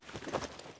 shared_hitreact_critical.wav